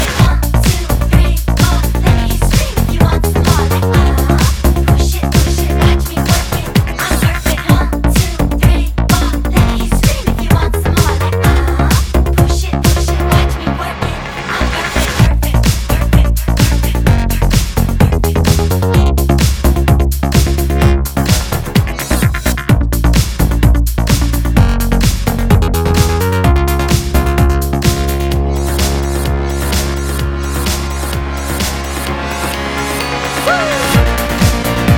Жанр: Танцевальные / Хаус
House, Dance